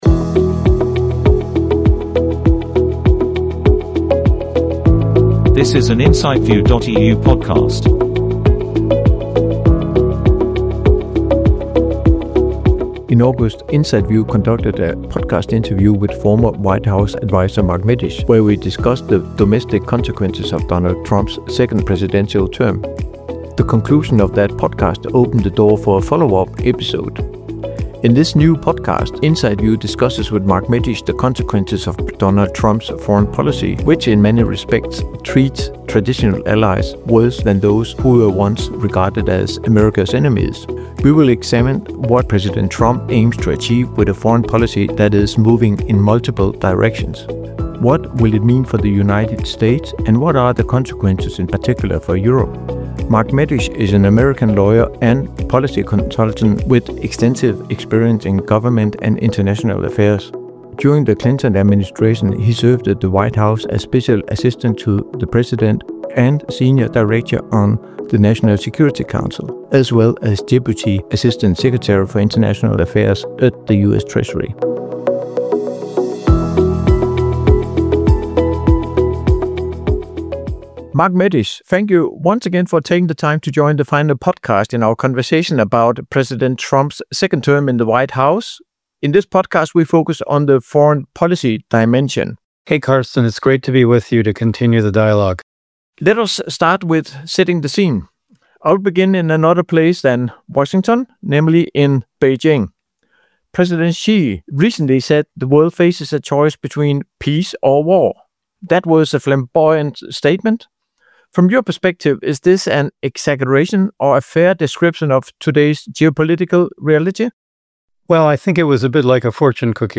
Podcast interview with former White House adviser Mark Medish: US Foreign Policy - Isolationism, Imperialism or Bullying?